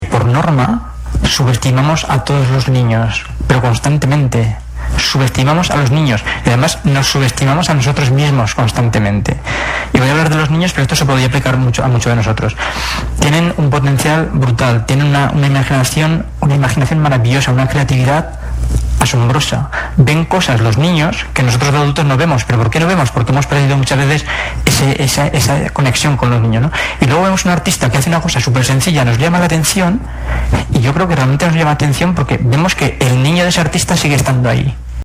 El prestigioso ponente desarrolló así una entretenida charla, rematada por un tiempo para el intercambio de opiniones y experiencias, presenciales y a través de Twitter a partir de su tesis fundamentada en que “todos tenemos diversos talentos, lo que no significa salir en un concurso de televisión. Es algo que te hace tender hacia tu felicidad y que se debe usar para colaborar y contribuir a construir una sociedad mejor”.